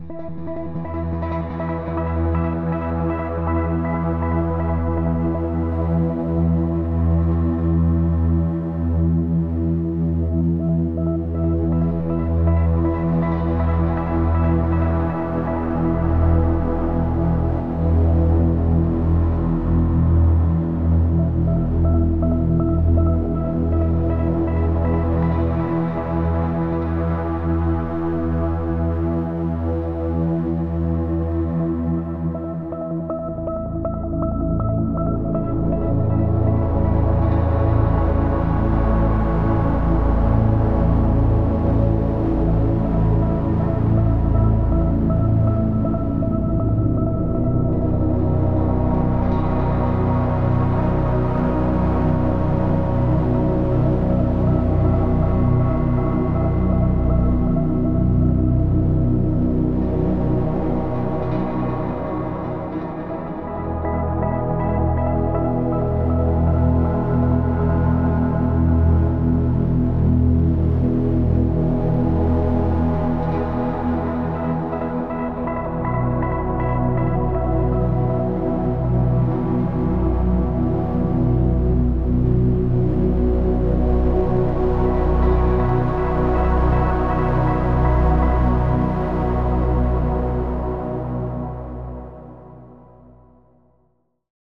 Added Ambient music pack. 2024-04-14 17:36:33 -04:00 26 MiB Raw Permalink History
Ambient Joy Intensity 1.wav